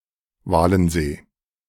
Lake Walen, also known as Lake Walenstadt or Walensee (German pronunciation: [ˈvaːln̩ˌzeː]
De-Walensee.ogg.mp3